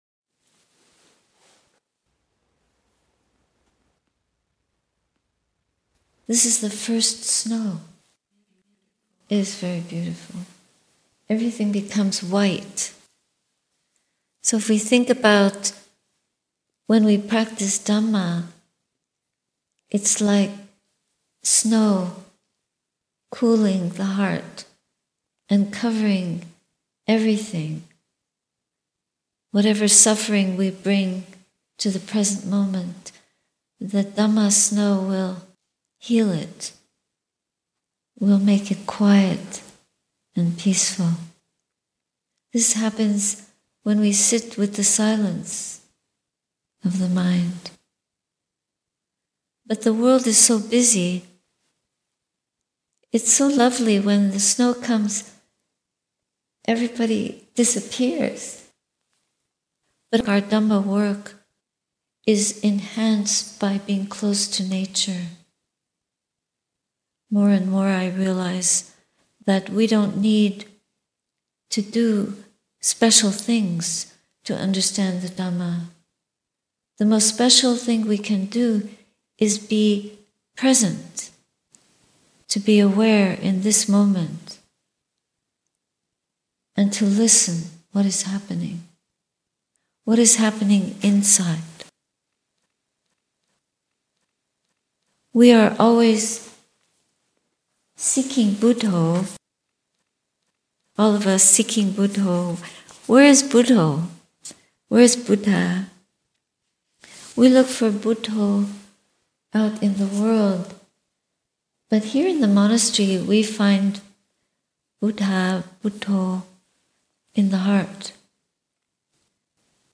Sunday reflections at Sati Saraniya Hermitage.